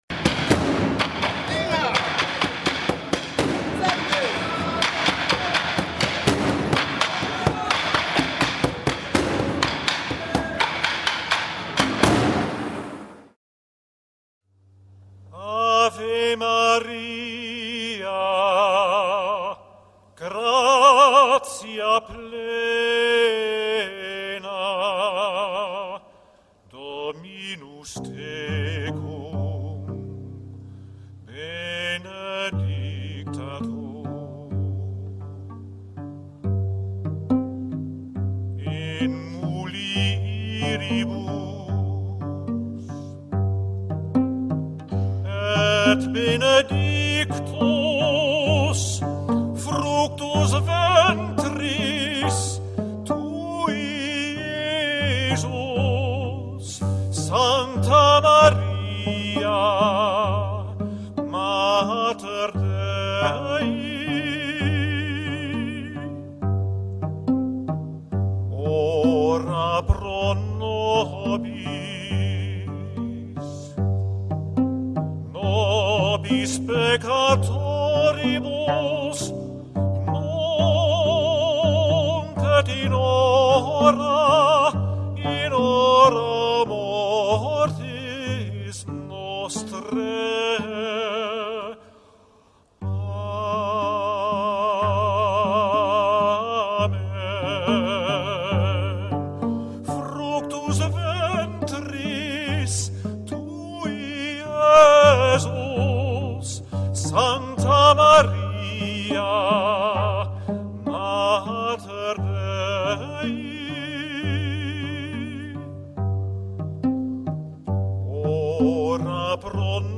Variety #76 – Burundian Drummers ( 03min) >>> Play
On this bright sunny day of Pentecost the prayer day began at 1 p.m. at the Beurs van Berlage and opened with the Rosary.
The Burundian choir was performed with great enthusiasm by dancing and loud drumming. This created a unique and special atmosphere.